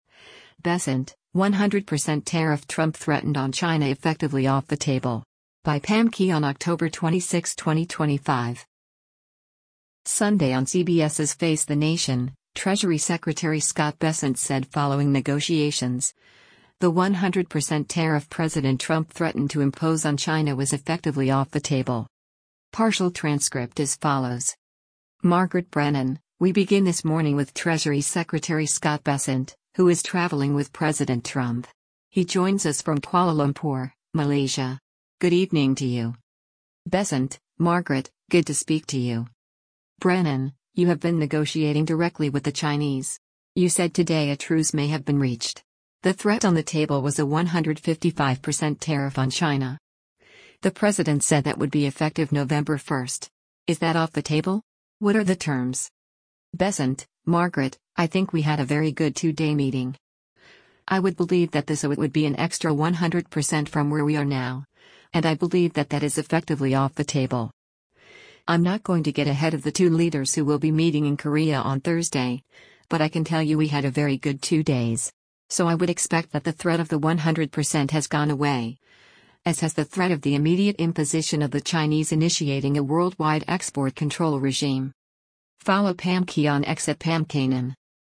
Sunday on CBS’s “Face the Nation,” Treasury Secretary Scott Bessent said following negotiations, the 100% tariff President Trump threatened to impose on China was “effectively off the table.”